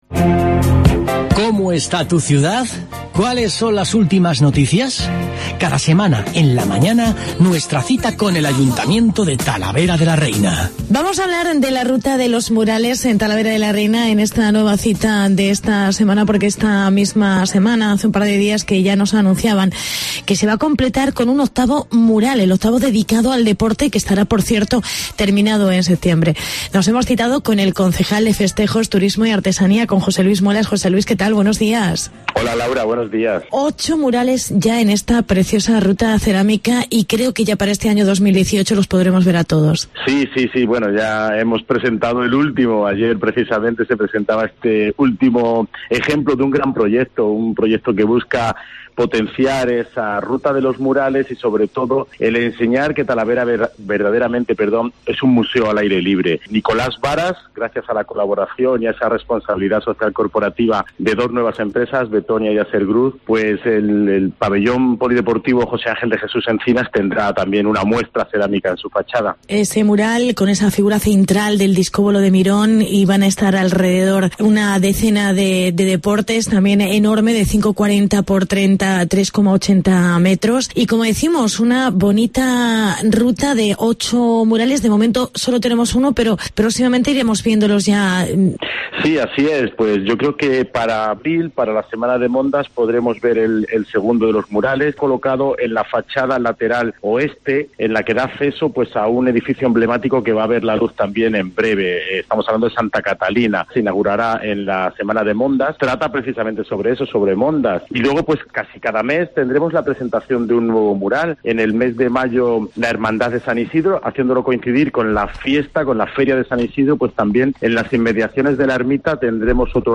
Entrevista al concejal José Luis Muelas